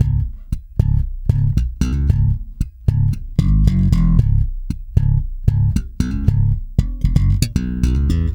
-JP THUMB C#.wav